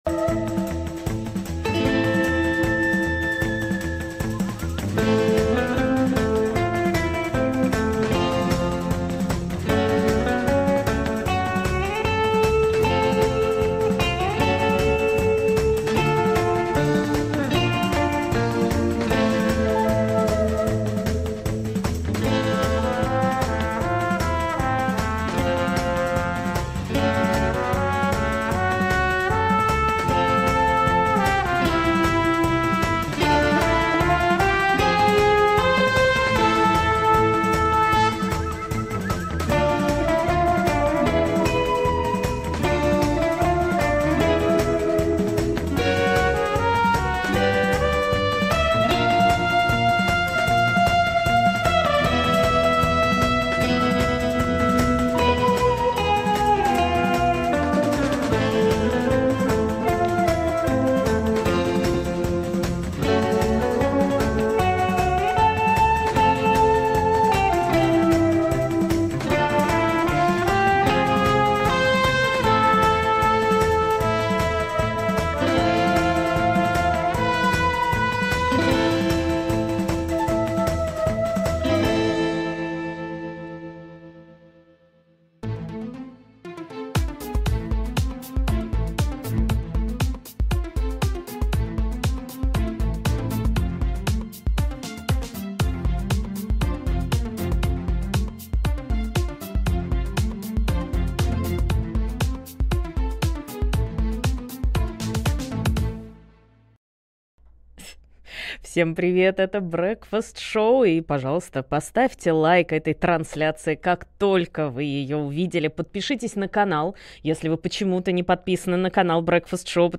обсудит с экспертами в прямом эфире The Breakfast Show все главные новости. Как Украина готовится к летнему наступлению РФ, и какие проблемы ждут ВС РФ в следующем году?